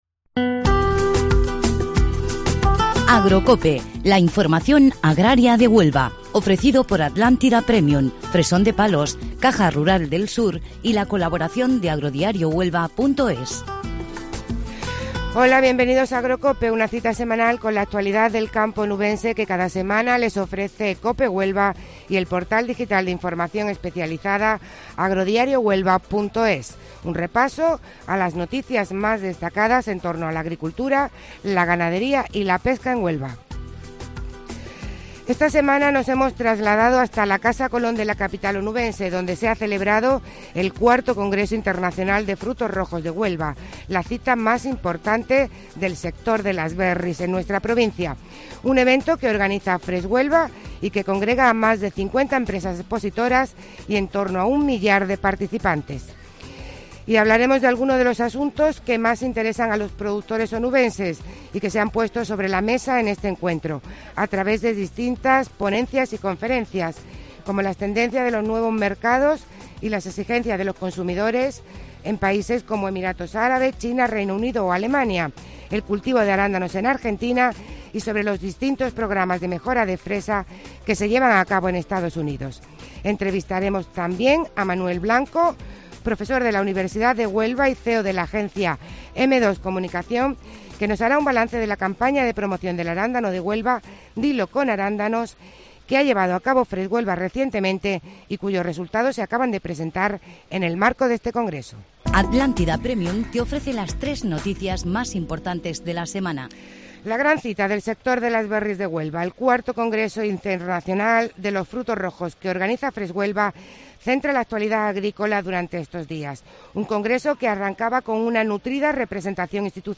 Esta semana nos trasladamos hasta la Casa Colón de la capital onubense donde se ha celebrado el IV Congreso Internacional de Frutos Rojos de Huelva, la cita más importante del sector de las berries de Huelva.